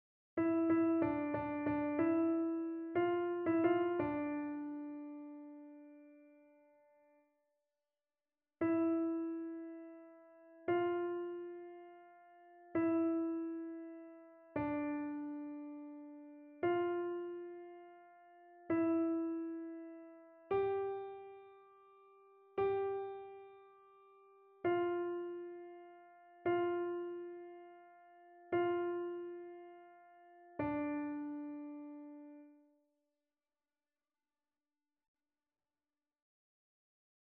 annee-a-temps-ordinaire-saint-sacrement-psaume-147-alto.mp3